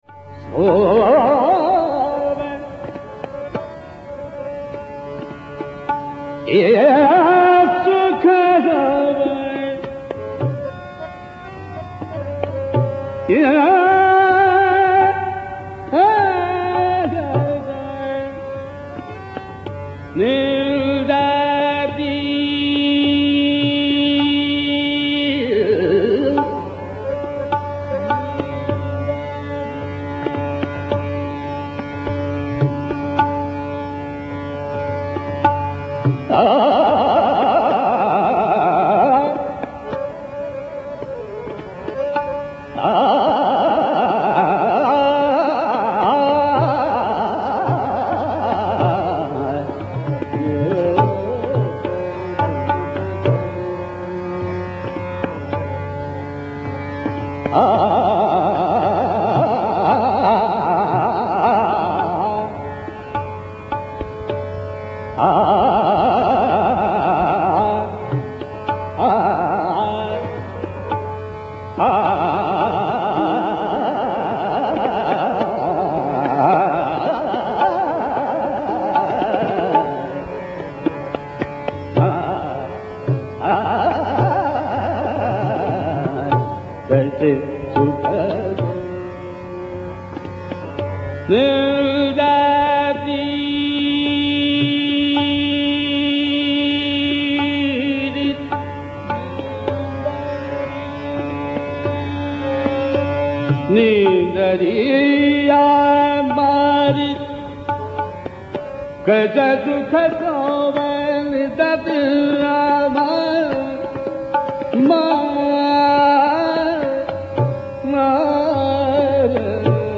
Sadarang’s khayalkaise sukha sove – is synonymous with Bihag and is presented in dheema Teentala by
Bhimsen Joshi.
Notice the dalliance with the komal nishad at 4:30.